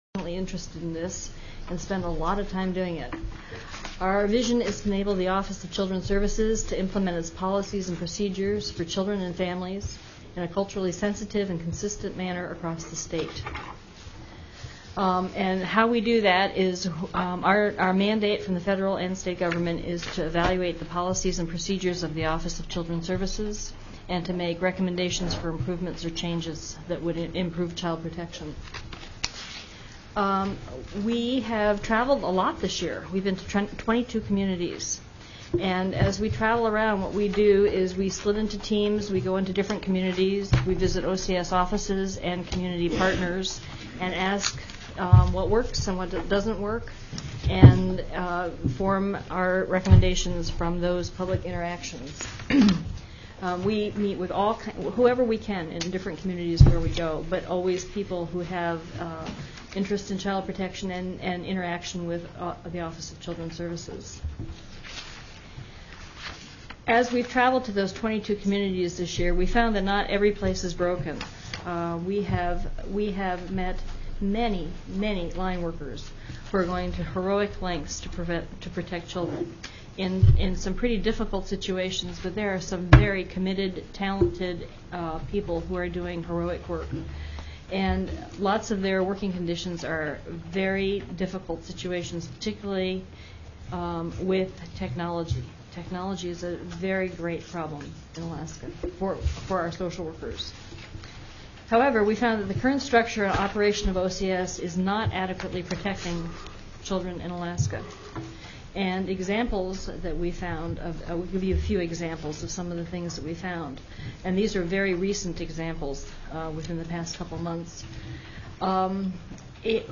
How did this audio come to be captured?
02/14/2008 03:00 PM House HEALTH, EDUCATION & SOCIAL SERVICES